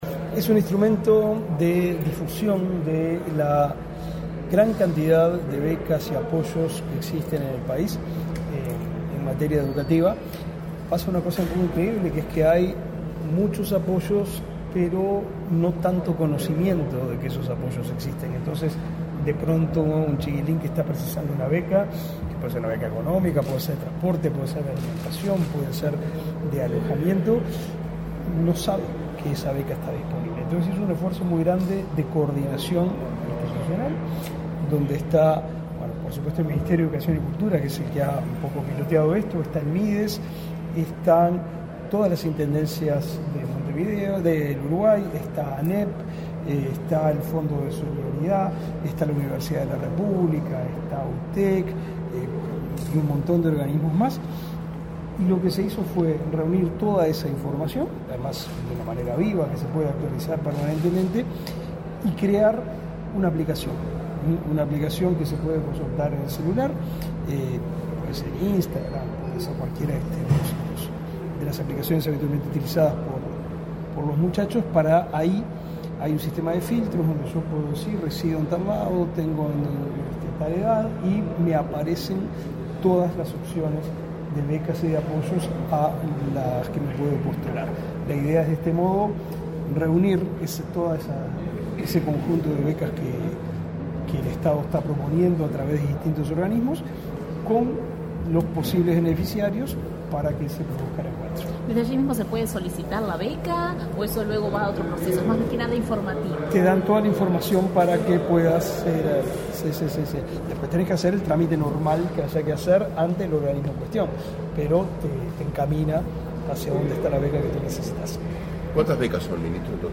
Declaraciones a la prensa del ministro del MEC, Pablo da Silveira